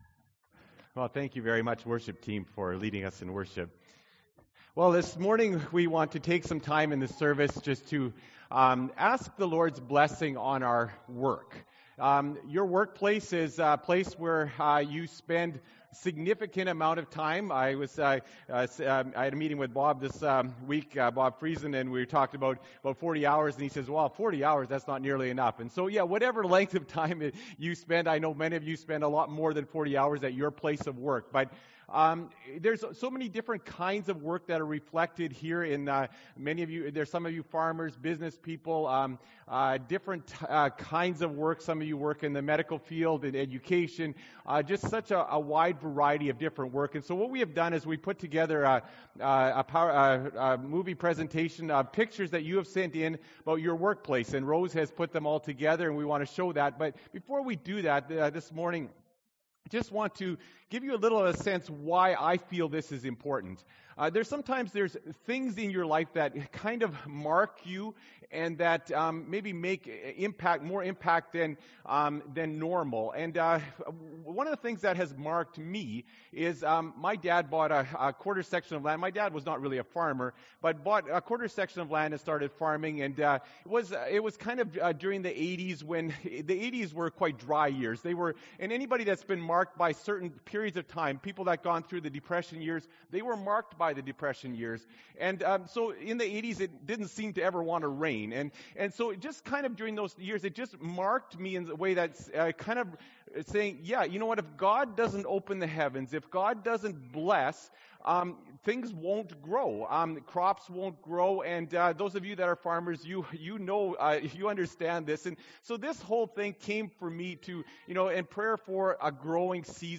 May 1, 2016 – Sermon